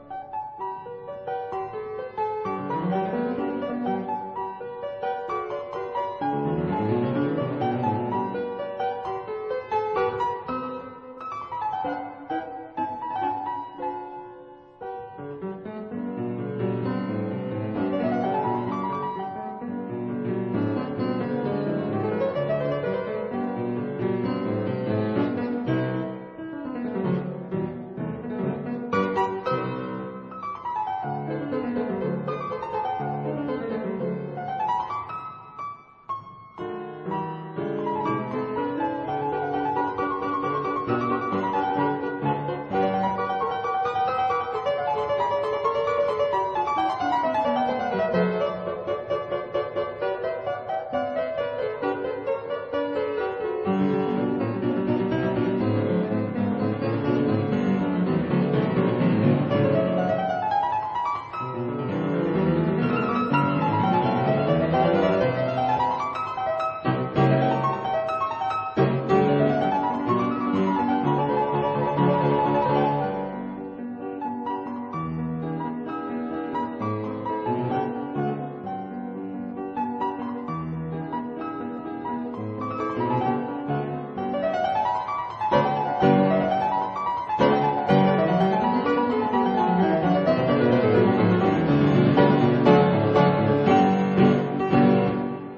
四手聯彈，如影隨形
這是在古鋼琴上（Pianoforte）演奏的四手聯彈。
四手聯彈在鋼琴上的變化，非常豐富。
第二雙手則是如影隨形，或重複、或疊聲、或陪伴哼唱。